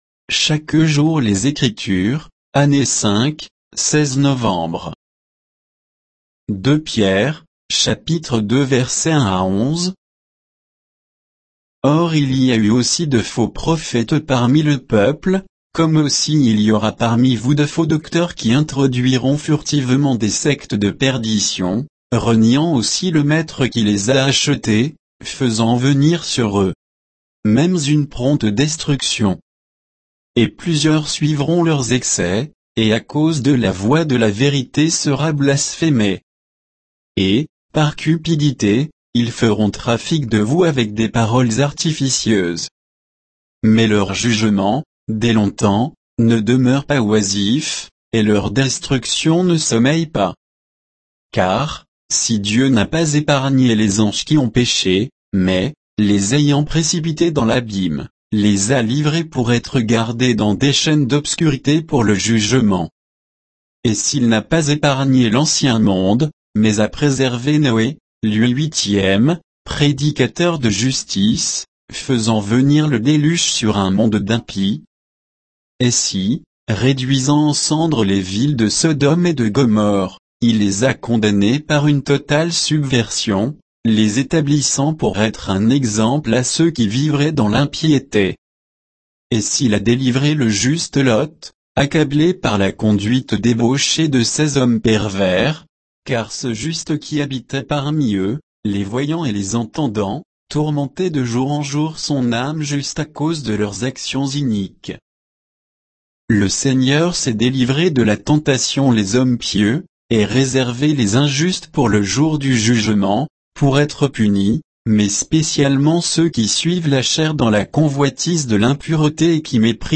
Méditation quoditienne de Chaque jour les Écritures sur 2 Pierre 2